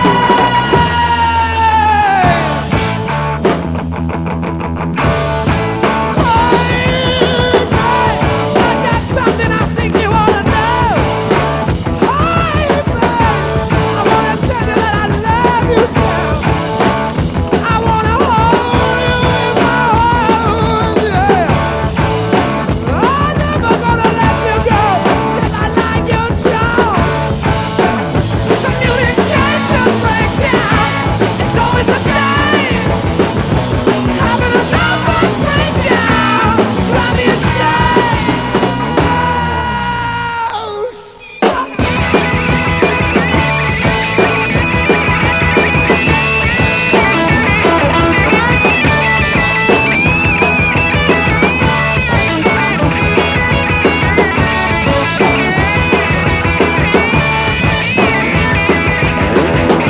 ROCK / 60'S / GARAGE PUNK / MOD / FLAT ROCK / DRUM BREAK
イケイケのグルーヴィー・チューン！